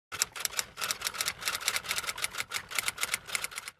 Typewriter Sound Effect Free Download
Typewriter